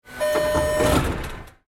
Subway Door Warning Tones And Mechanical Shut Sound Effect
Use this realistic subway audio to add an authentic metro or urban transit feel to your projects. It features warning tones followed by a solid mechanical door shut.
Subway-door-warning-tones-and-mechanical-shut-sound-effect.mp3